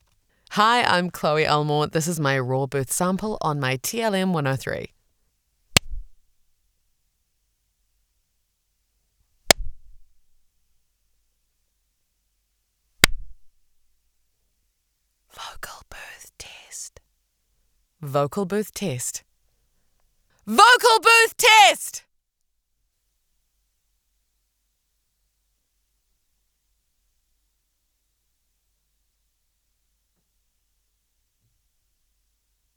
Female
American English , Australian English , British English , New Zealand English , Canadian English
Approachable, Assured, Authoritative, Bright, Bubbly, Character, Cheeky, Children, Confident, Conversational, Cool, Corporate, Deep, Energetic, Engaging, Friendly, Funny, Gravitas, Natural, Posh, Reassuring, Sarcastic, Smooth, Soft, Streetwise, Upbeat, Versatile, Wacky, Warm, Witty, Young
Voice reels
Microphone: Neumann TLM 103 mic